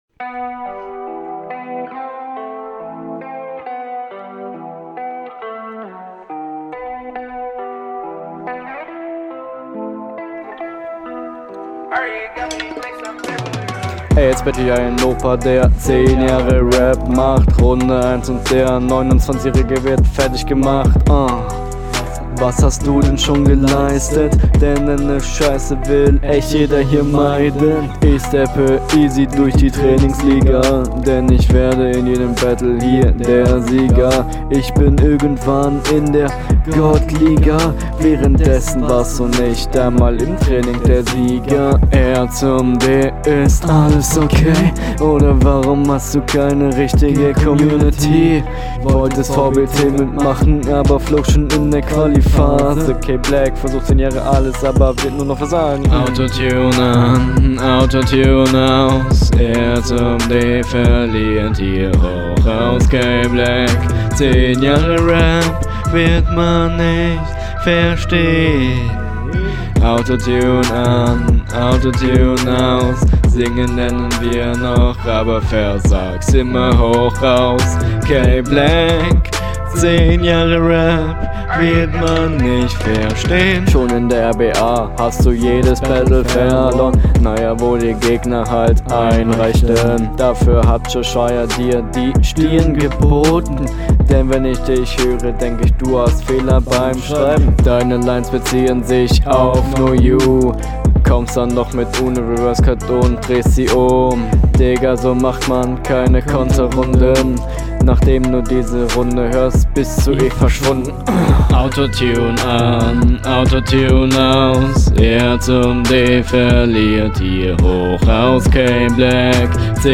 Der Flow ist der größte Kritikpunkt von dir.